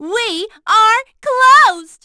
Juno-Vox_Skill5.wav